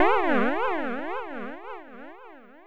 Magic3.wav